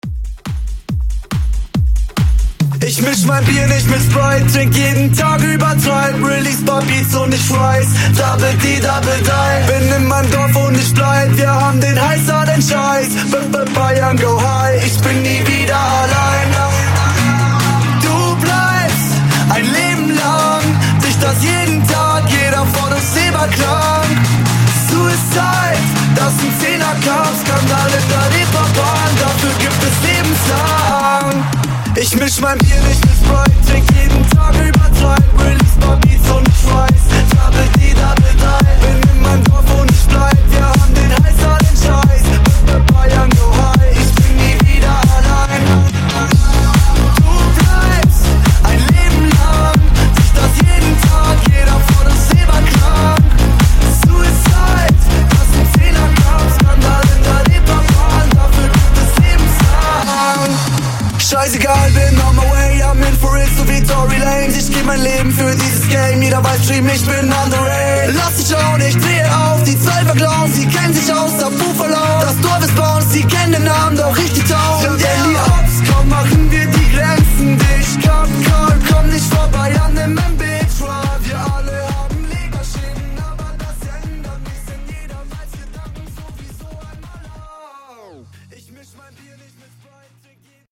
Genres: GERMAN MUSIC , RE-DRUM
Clean BPM: 161 Time